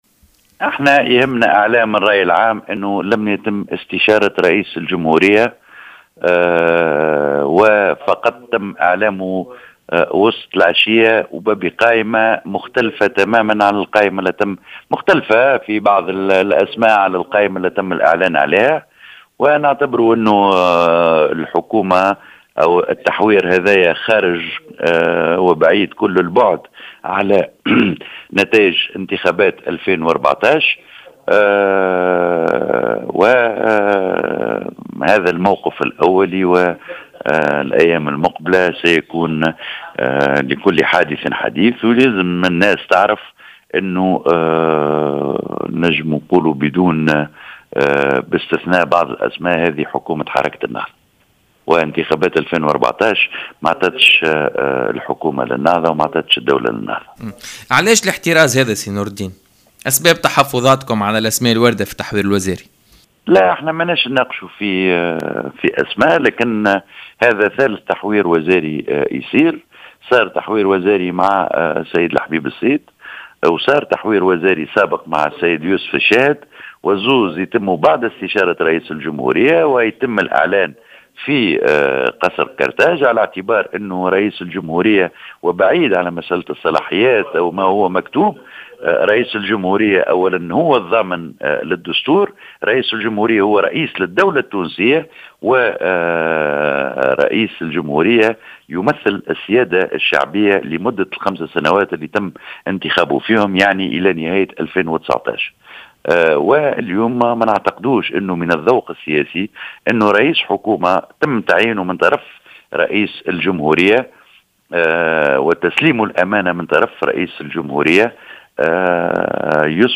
أكد المستشار لدى رئيس الجمهورية، نور الدين بن تيشة، في تصريح لـ "الجوهرة اف أم" مساء اليوم الاثنين أنه لم تتم استشارة رئيس الجمهورية بخصوص التحوير الوزاري، وفق تعبيره في اعلام للرأي العام.